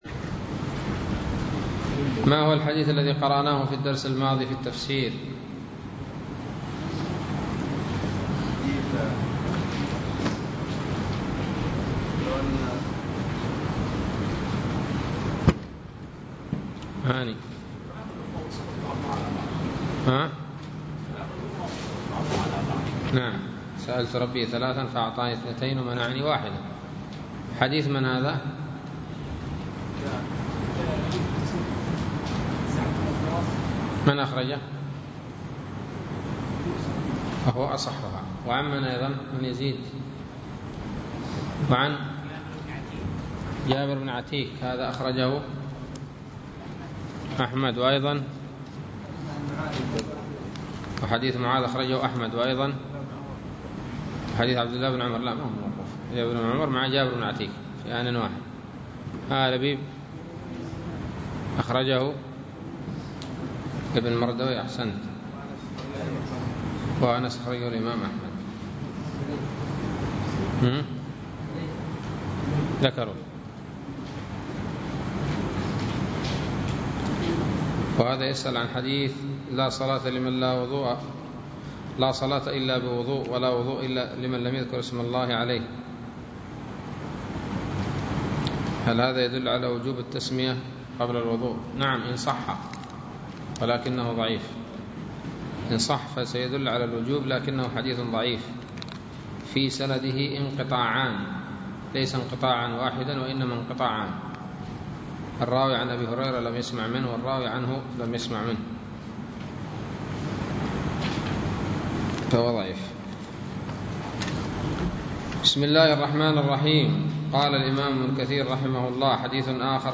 الدرس التاسع عشر من سورة الأنعام من تفسير ابن كثير رحمه الله تعالى